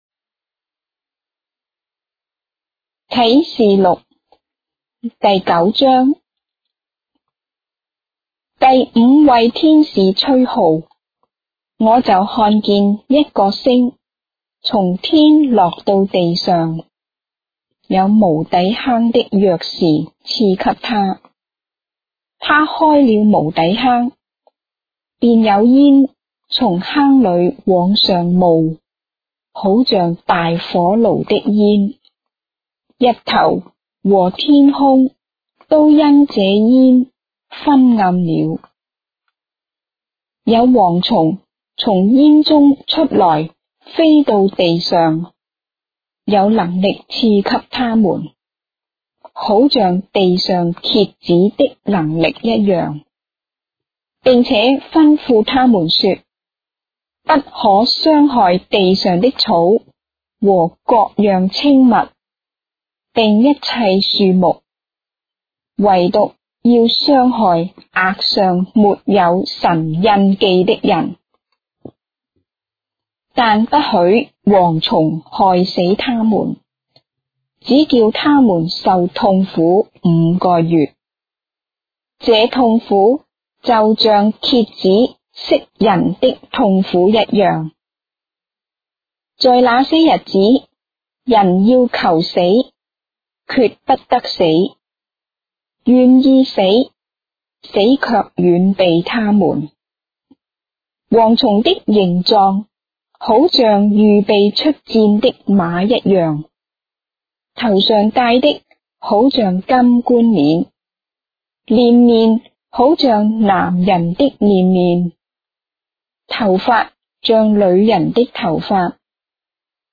章的聖經在中國的語言，音頻旁白- Revelation, chapter 9 of the Holy Bible in Traditional Chinese